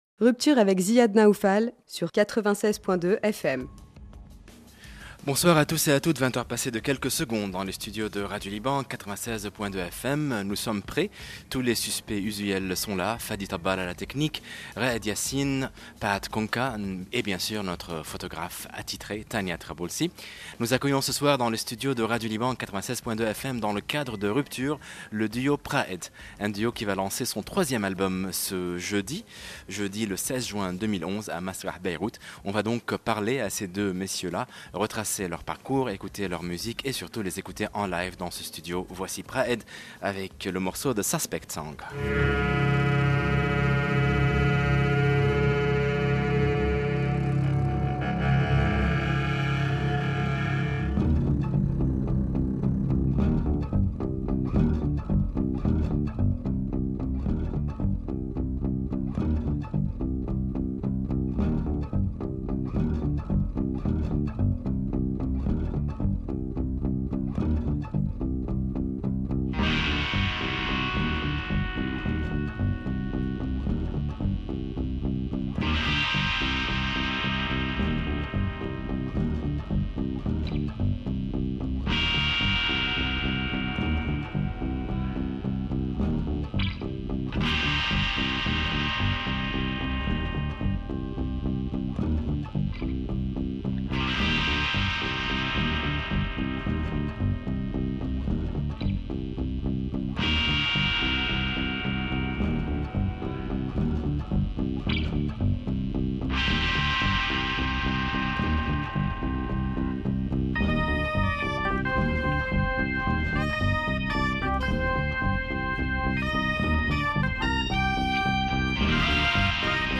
interview + live performance
electronic duo